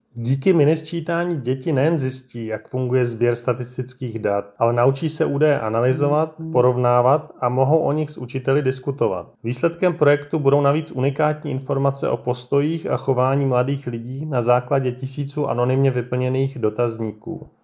Vyjádření Marka Rojíčka, předsedy Českého statistického úřadu, soubor ve formátu MP3, 691.88 kB